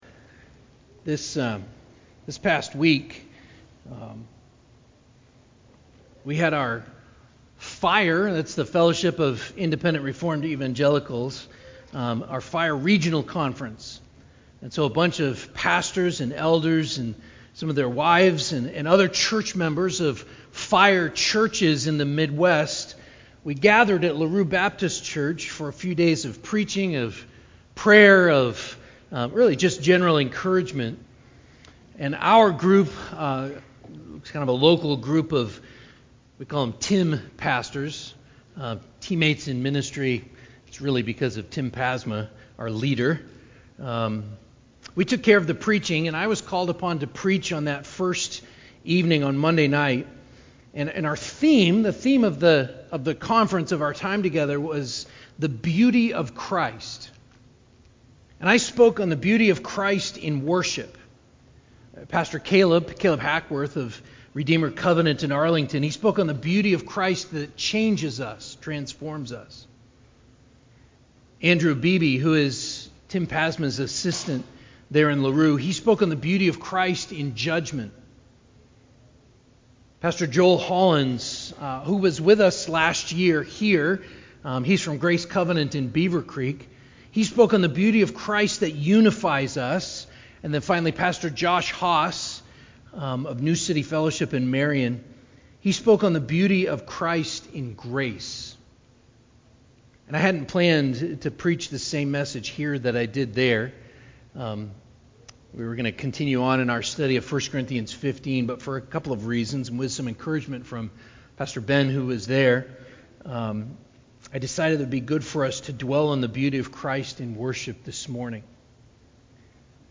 9.18.22-sermon-CD.mp3